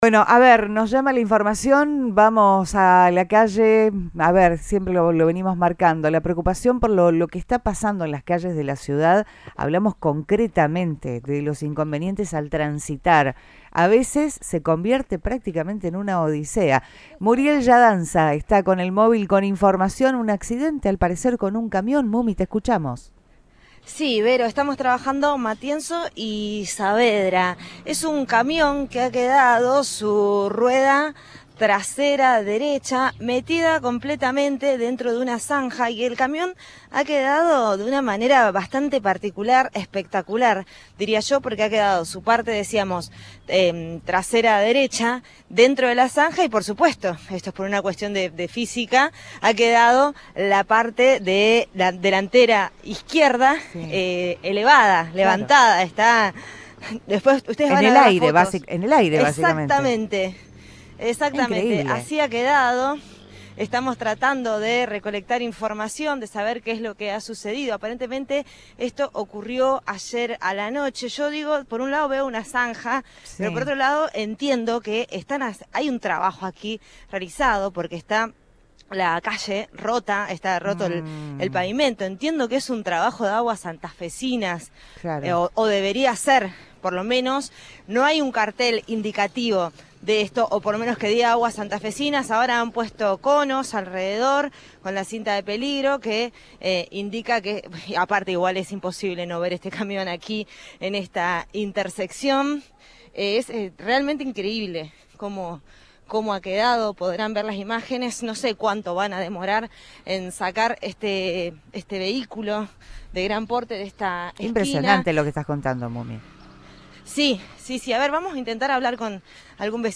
El hecho ocurrió en las últimas horas y los vecinos que dialogaron con el móvil de Cadena 3 Rosario, en Radioinforme 3, no supieron explicar el accidente.